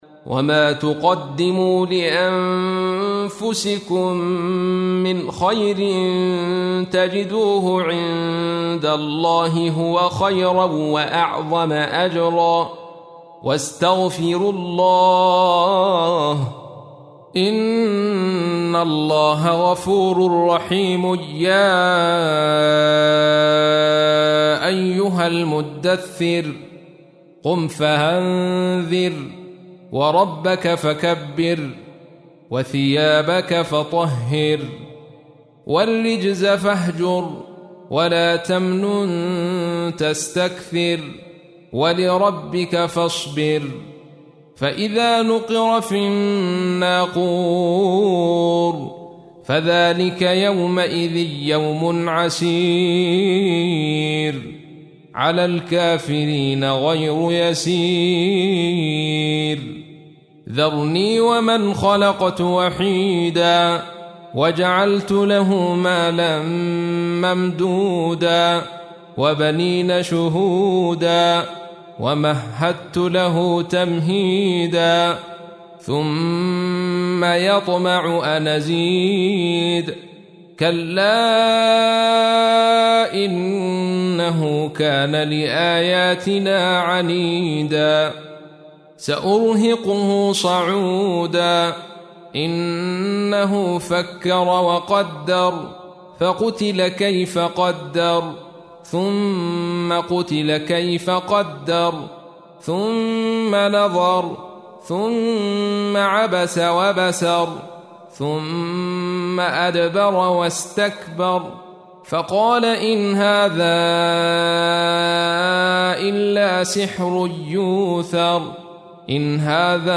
تحميل : 74. سورة المدثر / القارئ عبد الرشيد صوفي / القرآن الكريم / موقع يا حسين